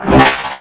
CosmicRageSounds / ogg / general / combat / enemy / droid / att1.ogg